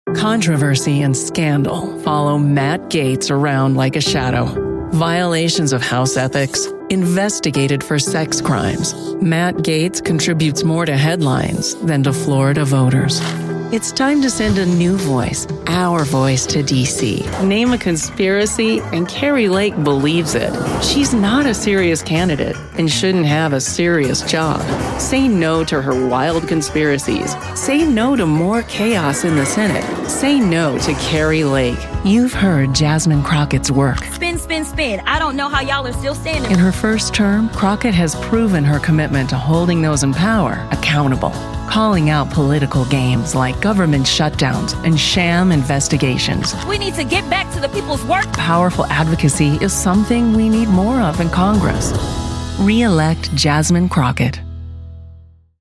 Inglês (dos Estados Unidos)
Anúncios políticos
Record-Ready professional sound booth
MIC - Sennheiser MK 4